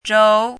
chinese-voice - 汉字语音库
zhou2.mp3